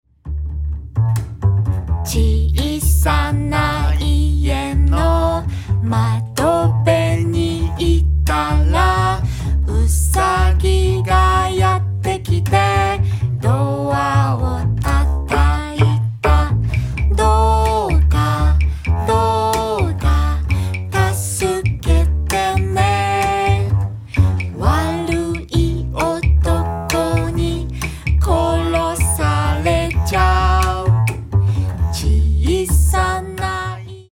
世界のわらべうたを楽しむ
素朴で心休まる音楽です。
ハンガリーのわらべうた